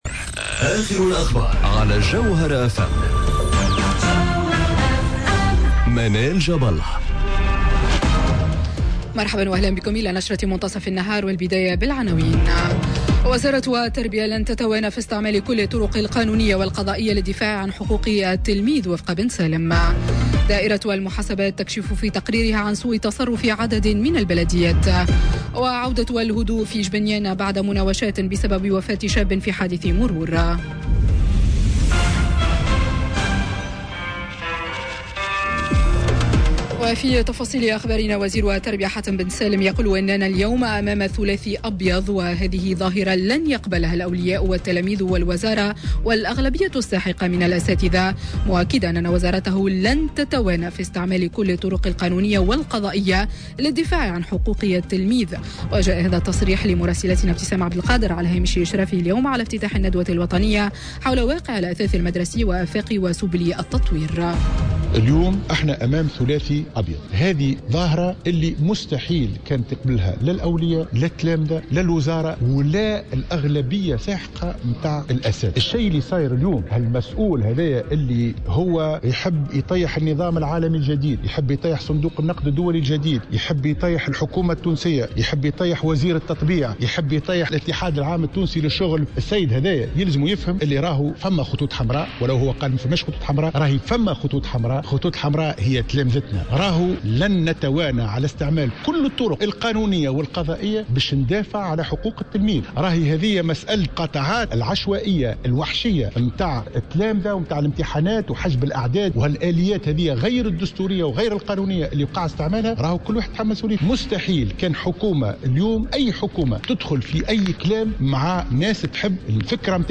نشرة أخبار منتصف النهار ليوم الإثنين 24 ديسمبر 2018